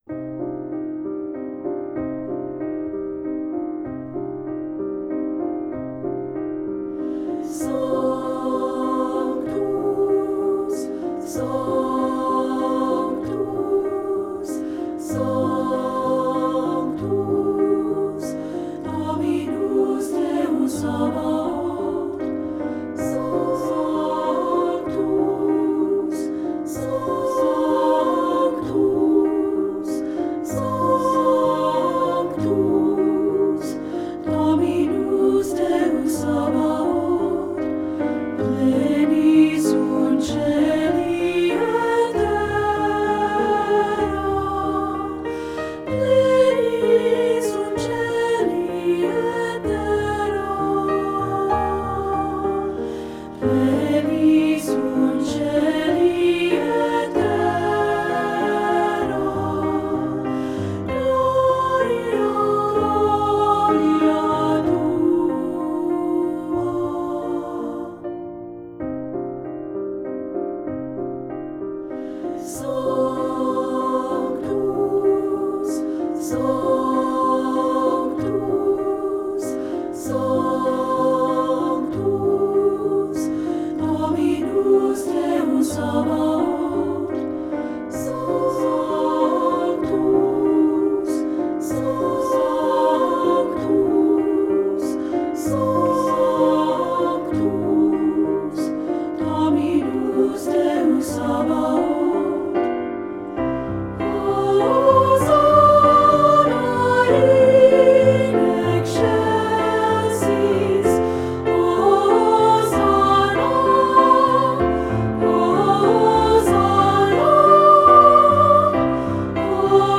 Voicing: Unison|2-Part and Piano